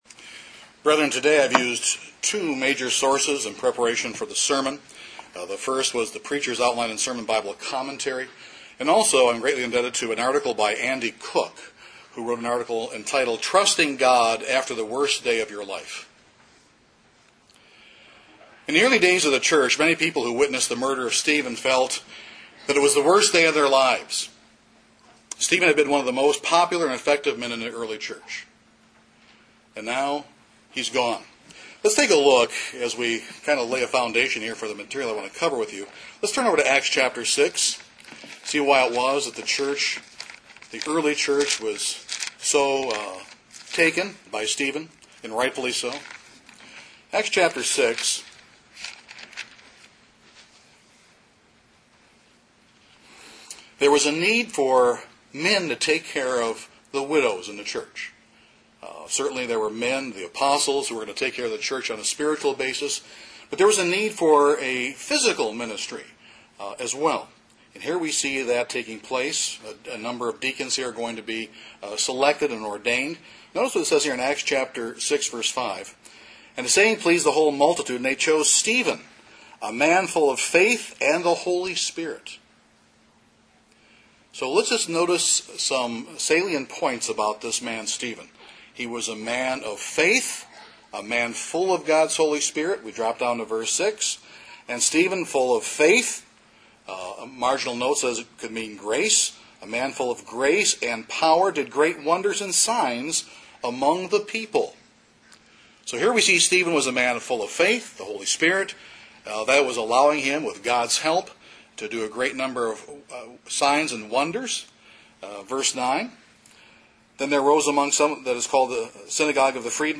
This sermon shows how the early New Testament Church dealt with such crushing circumstances yet managed to move forward and flourish!